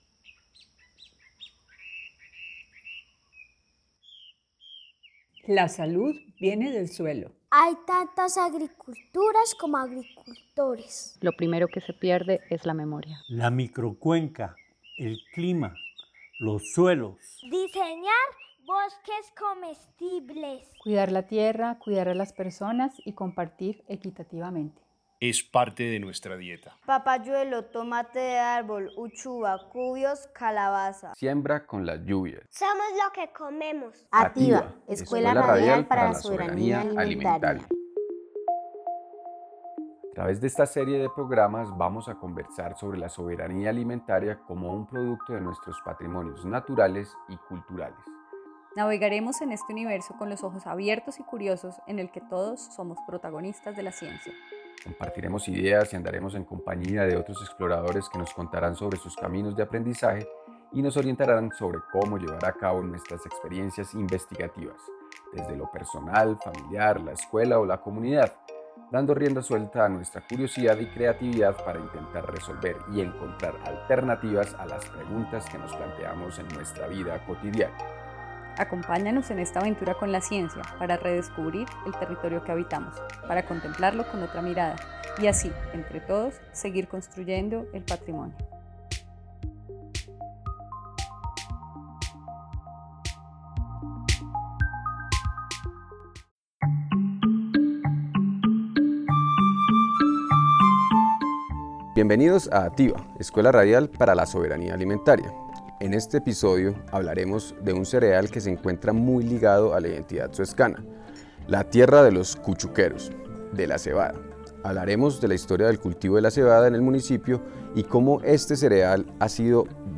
Programa radial capítulo seis.